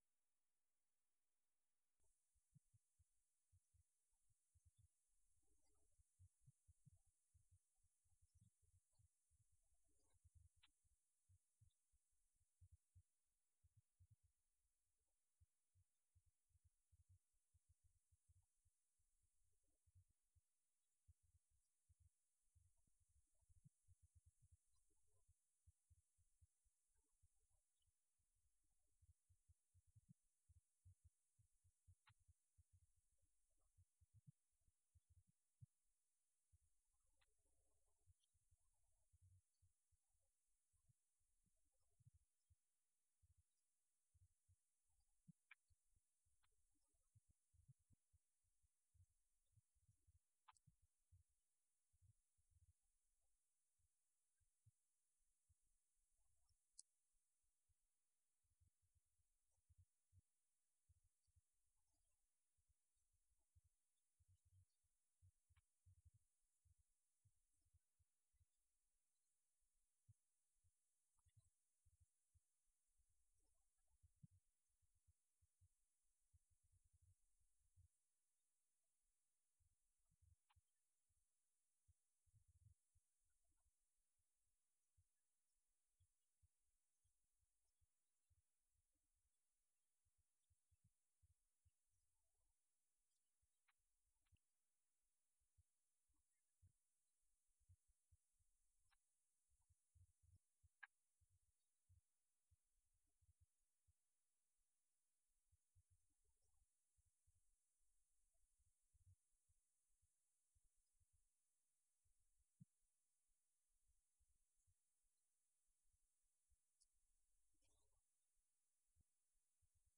Event: 5th Annual Arise Workshop
If you would like to order audio or video copies of this lecture, please contact our office and reference asset: 2022Arise12